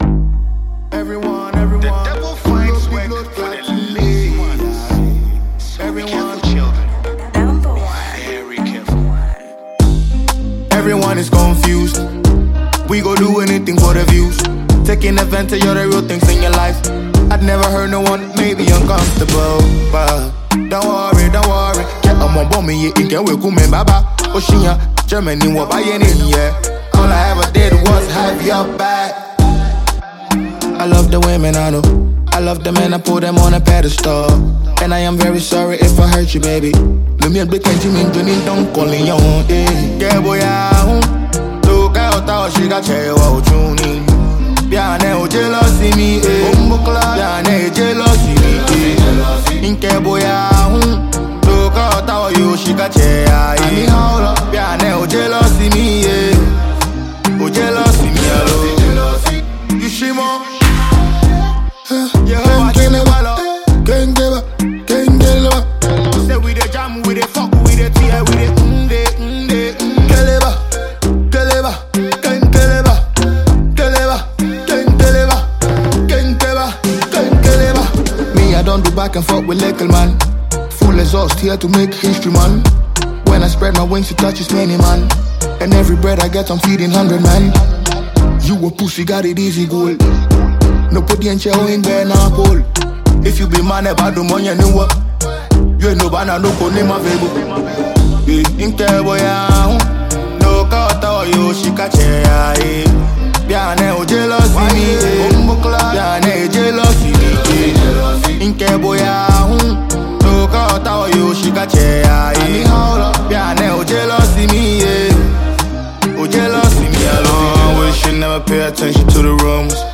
Ghana Music Music
Afrobeats/Afropop